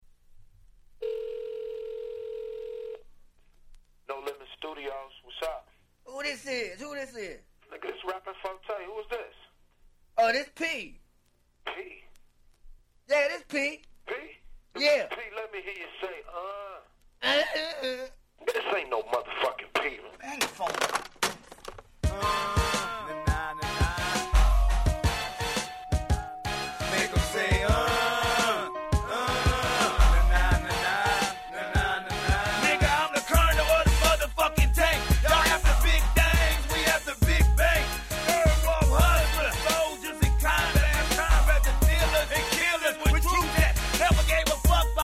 98' Super Hit Southern Hip Hop !!